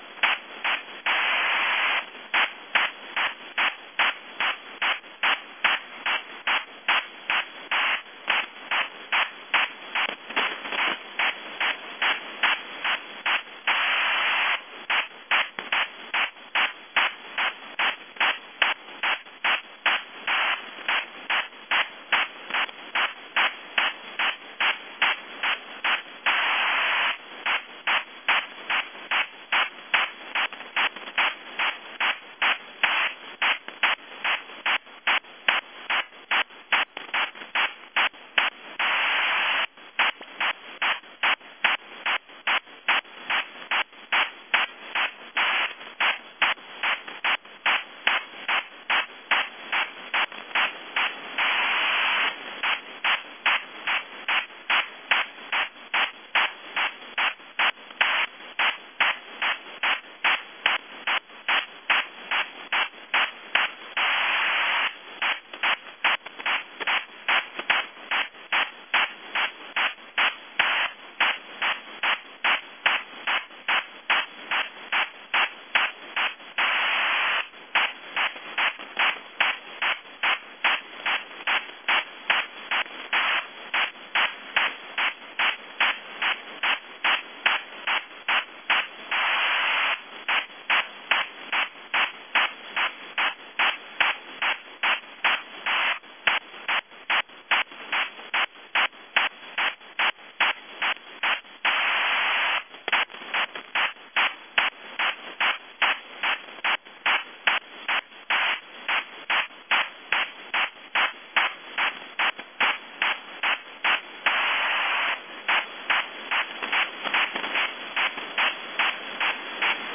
Link-11_slew_high.mp3